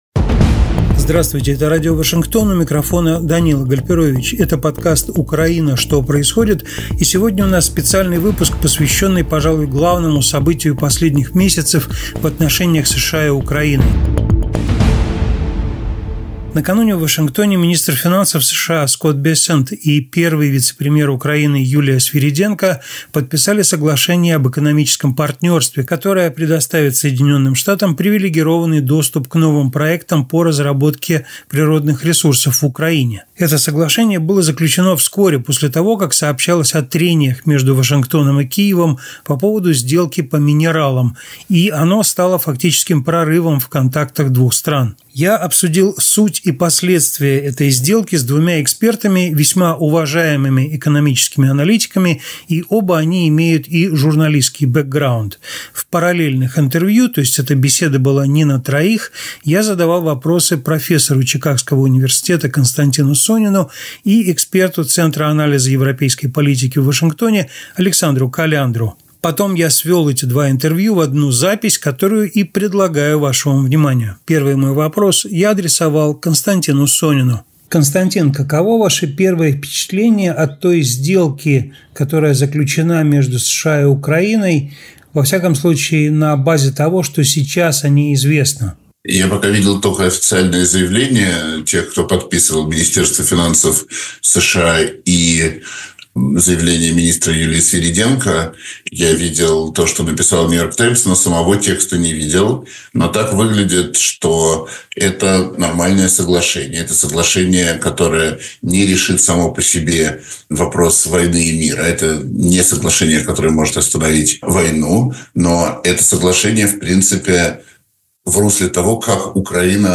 В этом выпуске — параллельные интервью с экономическими экспертами об экономической сделке между США и Украиной, подписанной 30 апреля.